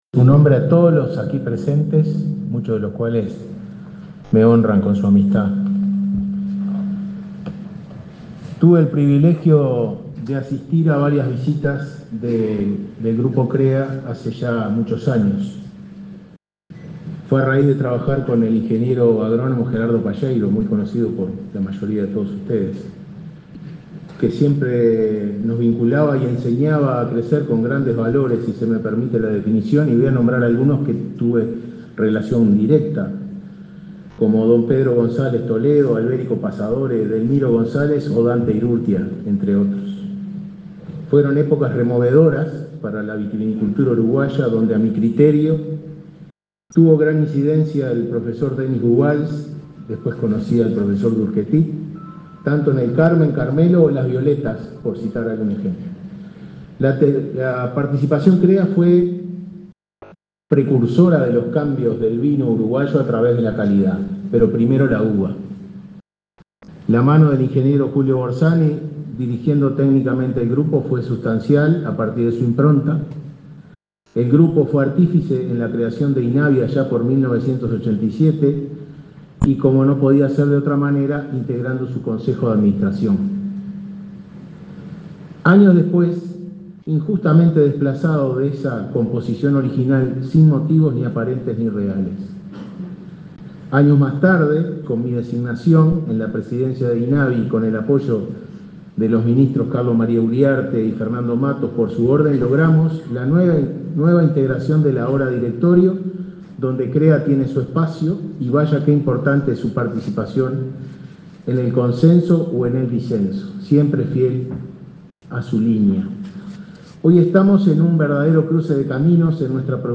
Palabras del presidente de INAVI, Ricardo Cabrera
Palabras del presidente de INAVI, Ricardo Cabrera 25/10/2024 Compartir Facebook X Copiar enlace WhatsApp LinkedIn Este 25 de octubre, el presidente de la República, Luis Lacalle Pou, participó en el 50.° aniversario del Grupo Crea-Vitivinicultura. En el evento disertó el titular del Instituto Nacional de Vitivinicultura (Inavi), Ricardo Cabrera.